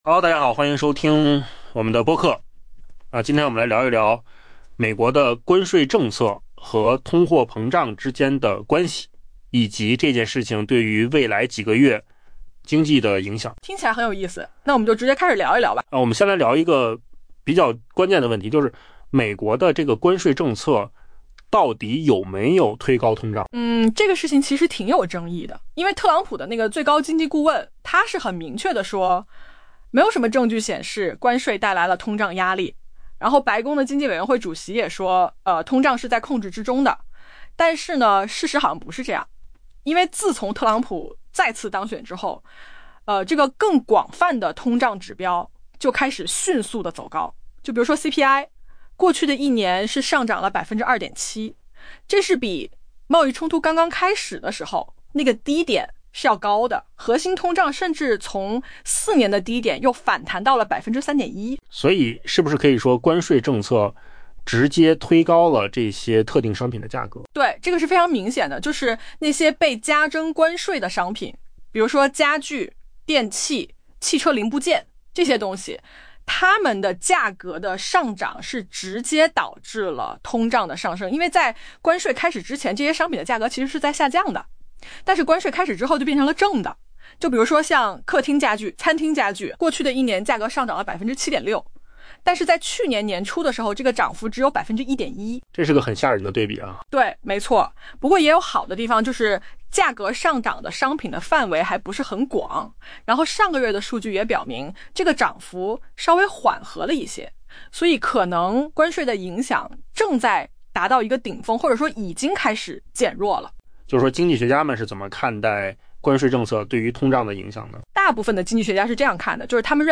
音频由扣子空间生成